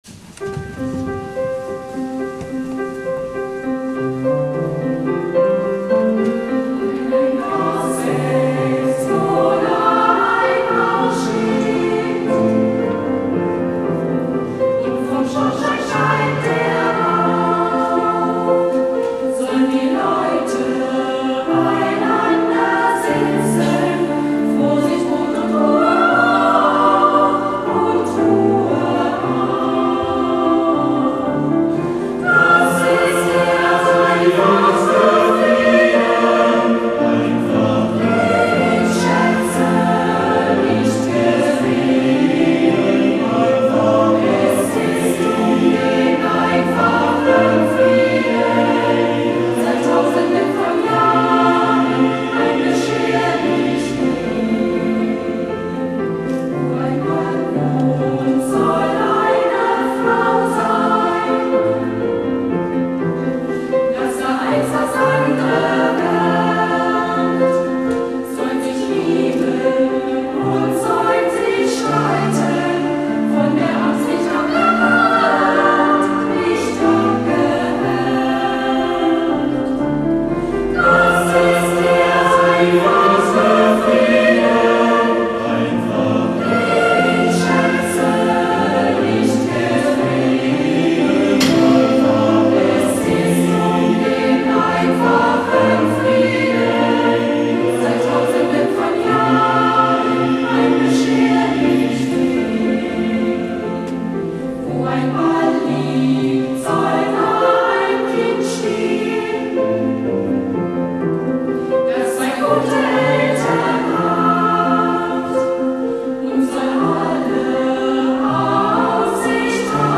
Als Download ist eine kleine Kostprobe aus dem Mitschnitt des Jubiläumskonzertes zu hören.
Klavier